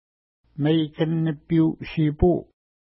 Pronunciation: meikən-nəpi:u-ʃi:pu: